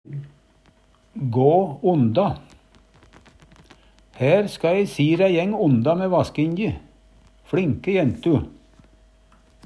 gå onda - Numedalsmål (en-US)